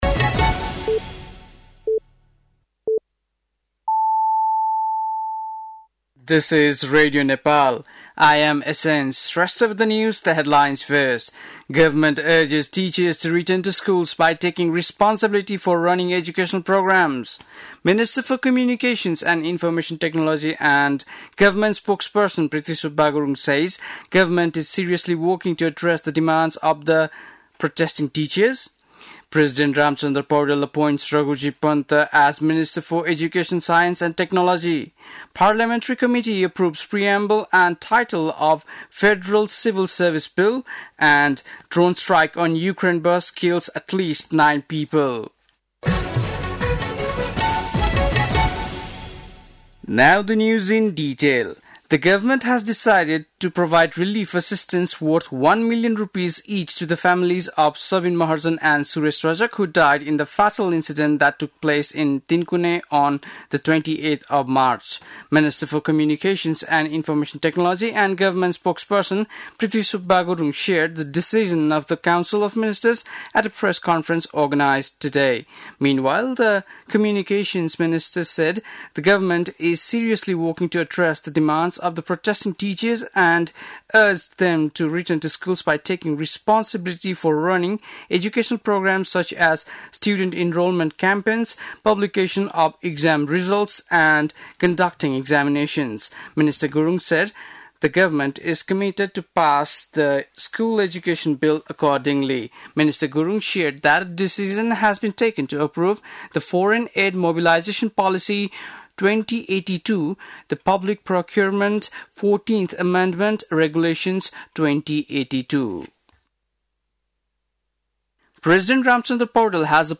बेलुकी ८ बजेको अङ्ग्रेजी समाचार : १० वैशाख , २०८२
8.-pm-english-news-1.mp3